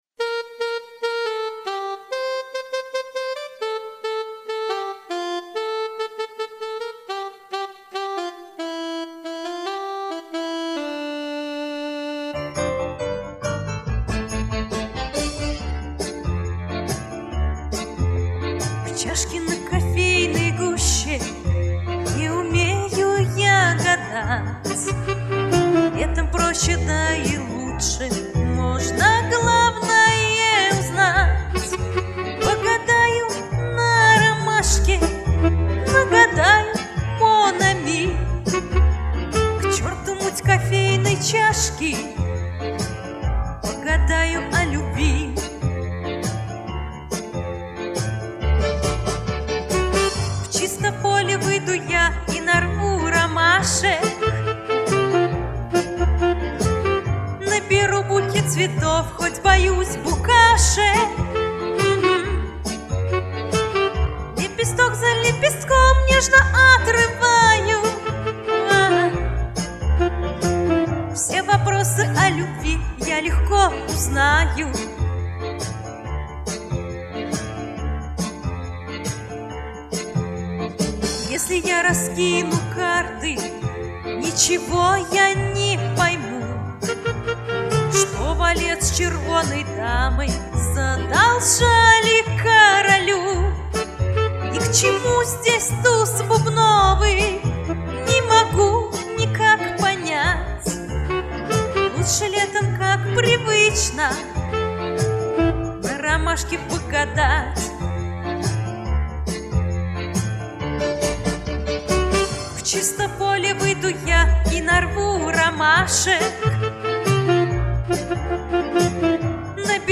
Русский поп-шансон